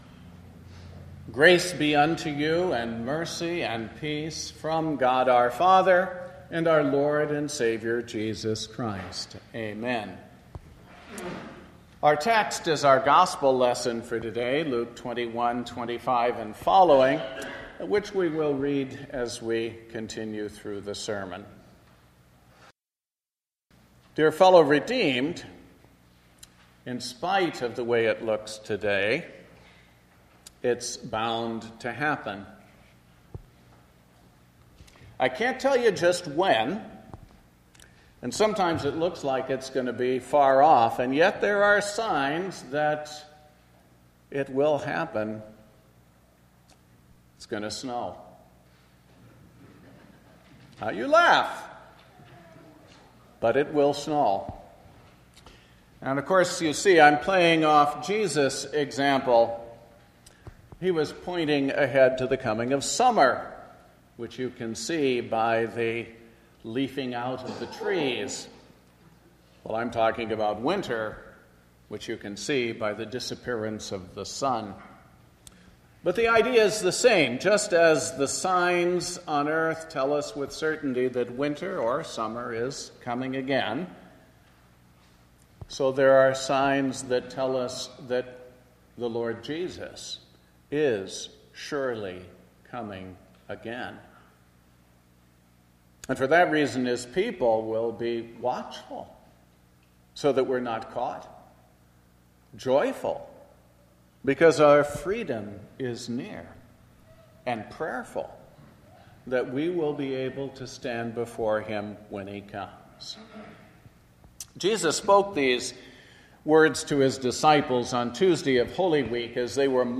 The First Sunday Of Advent